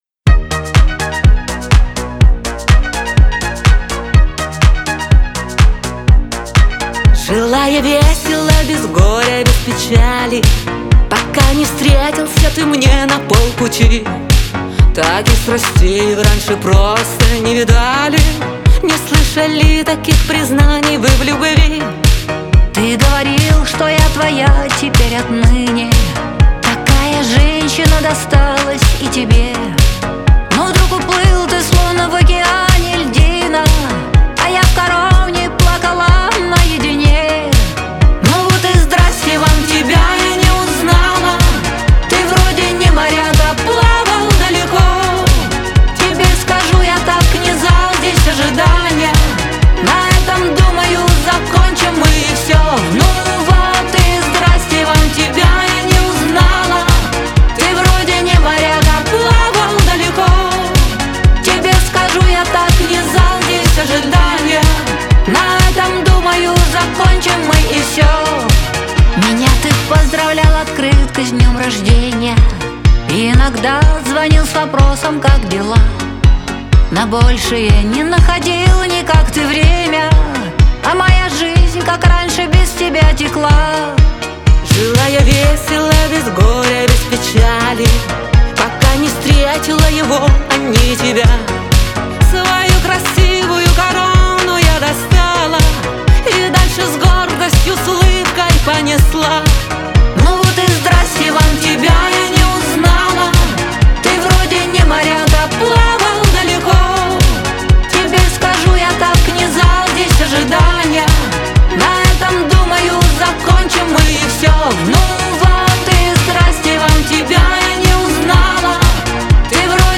pop
дуэт
эстрада